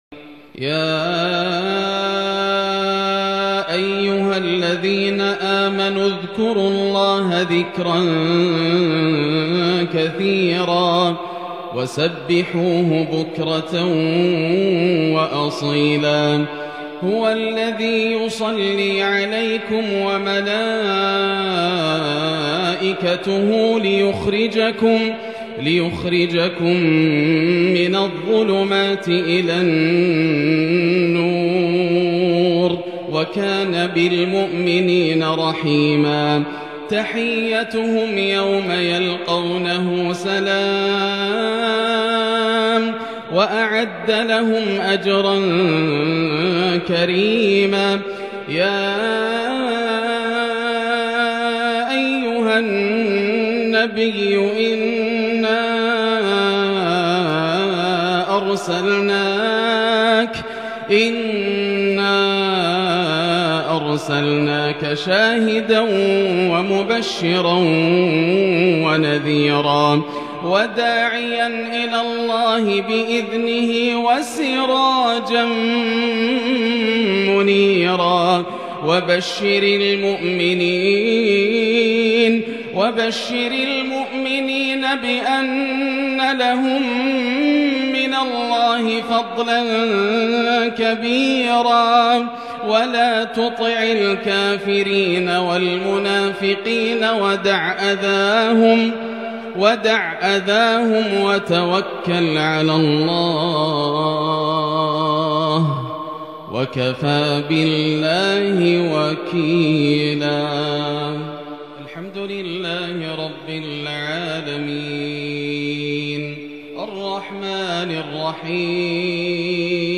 صلاة المغرب 1-6-1442 هـ من سورة الاحزاب | Maghrib prayer from Surat Al-Ahzab 14/1/2021 > 1442 🕋 > الفروض - تلاوات الحرمين